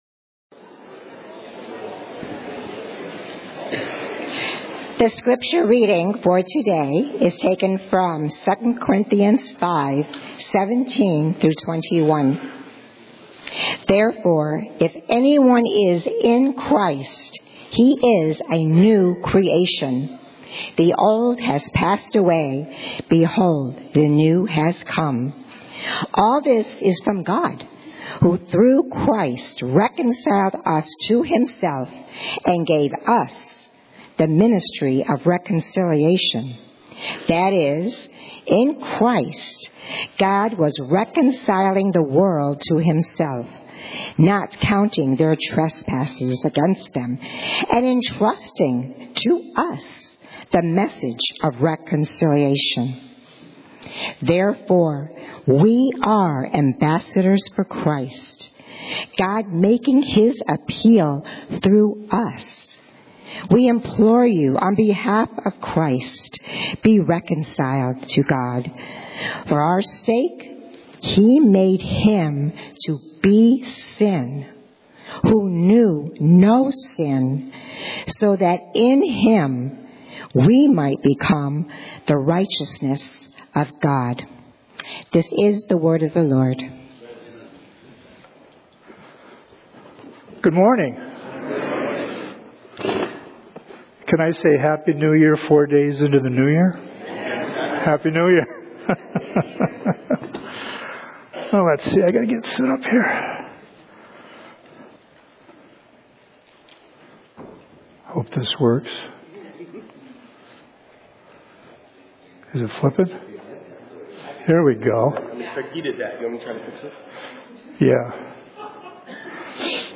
Series: 2026 Sermons